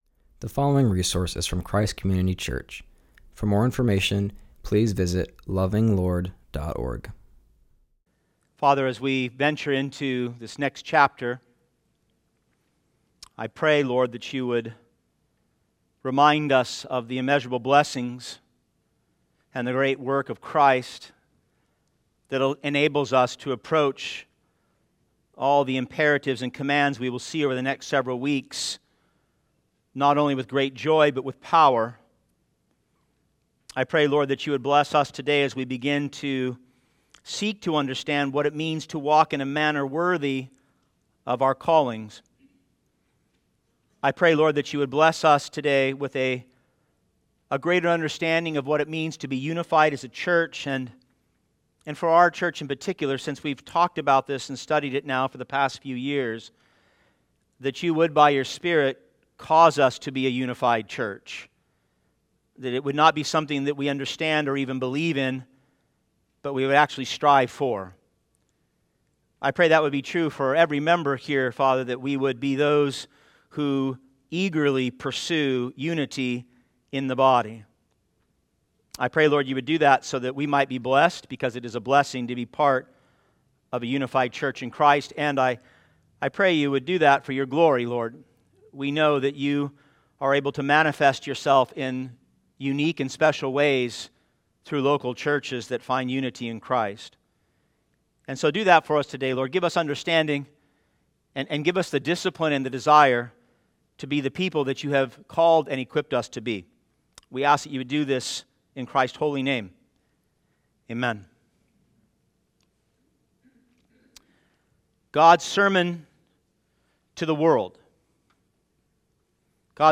preaches from Ephesians 4:1-6.